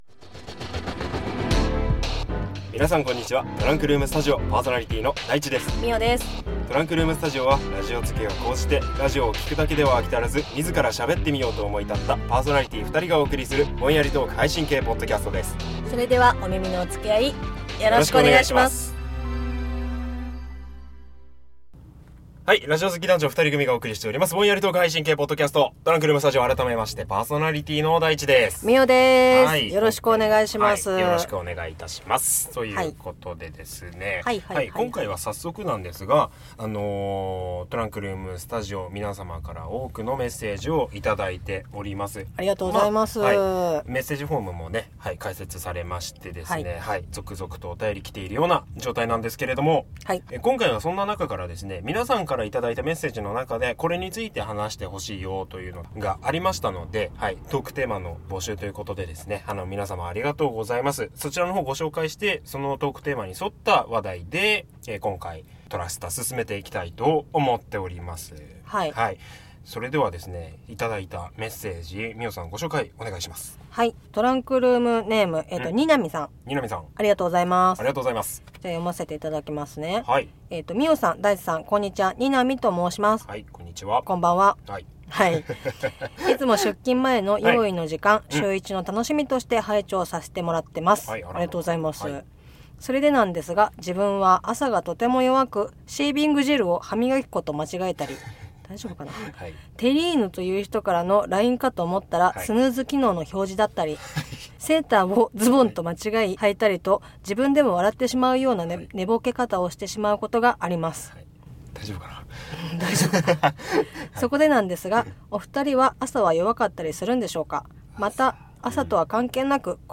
第９回【トランクルーム・スタジオ】 今回は、リスナーさんからのお便りを元に、ぼんやりトーク！ ポンコツな二人の朝の過ごし方や苦手なものについてお話してます！